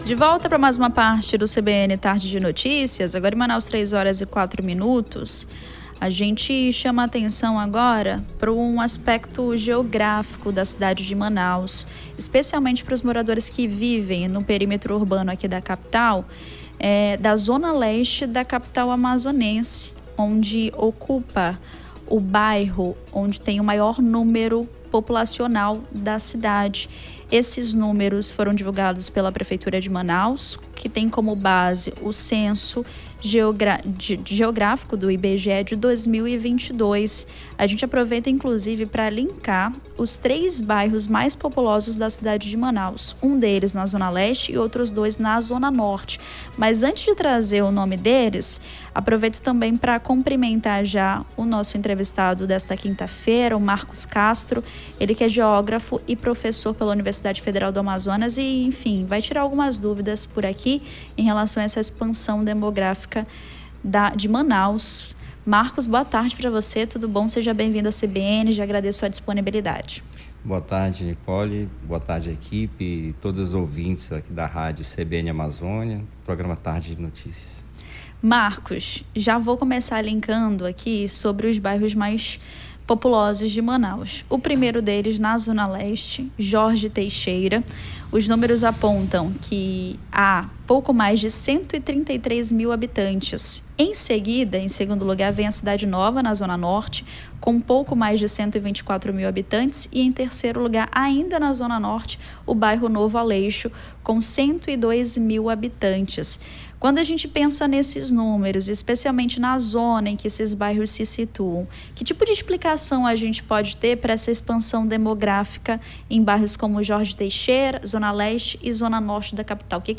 Bairros mais habitados de Manaus se concentram nas zonas Norte e Leste; confira entrevista